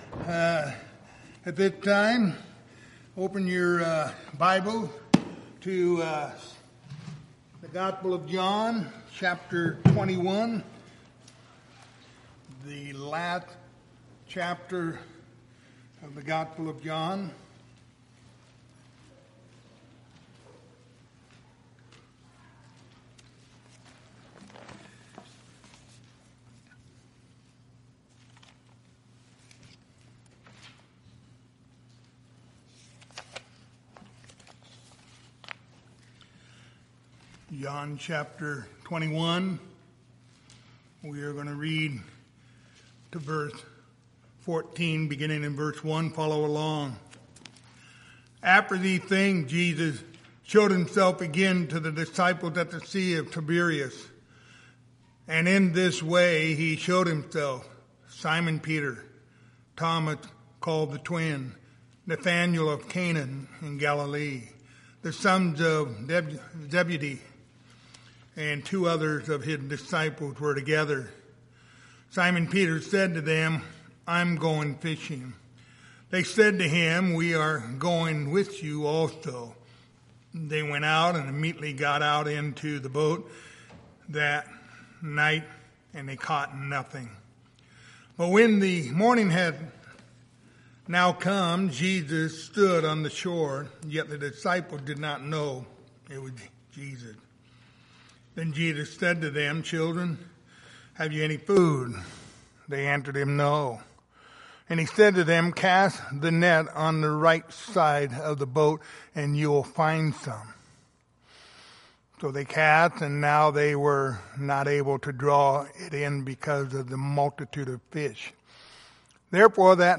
Passage: John 21:1-14 Service Type: Wednesday Evening